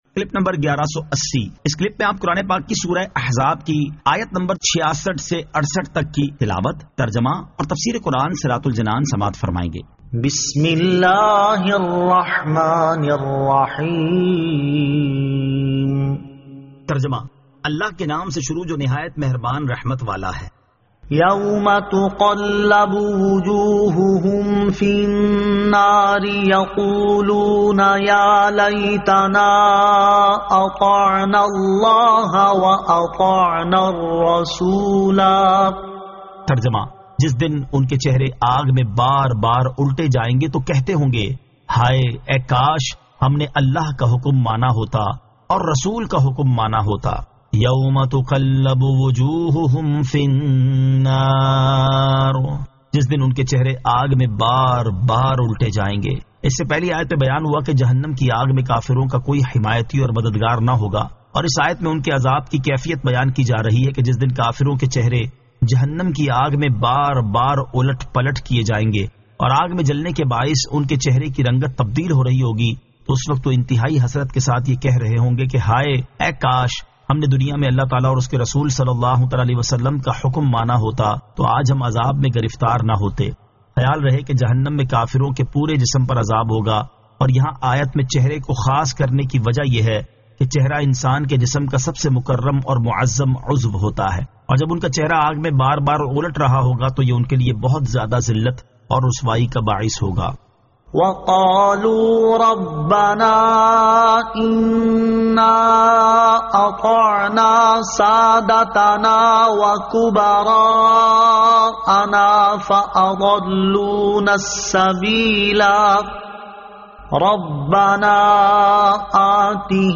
Surah Al-Ahzab 66 To 68 Tilawat , Tarjama , Tafseer
2023 MP3 MP4 MP4 Share سُوَّرۃُ الٗاحٗزَاب آیت 66 تا 68 تلاوت ، ترجمہ ، تفسیر ۔